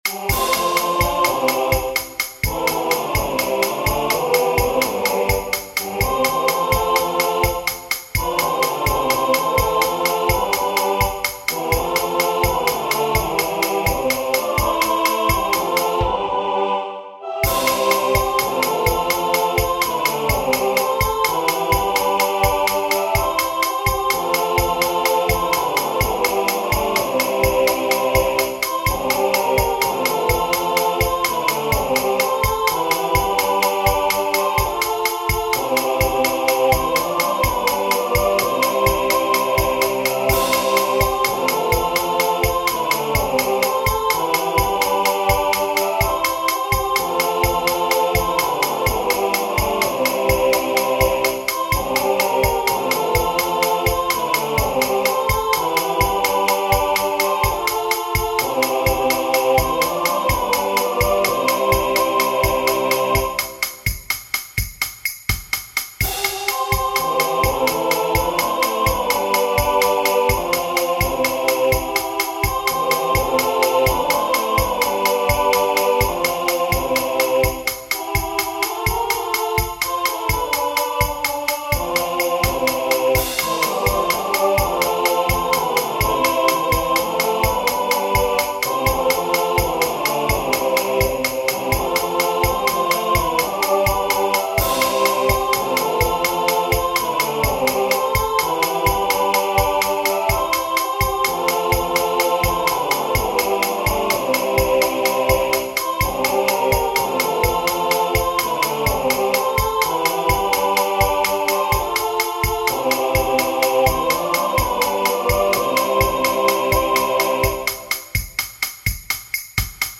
Chants de Méditation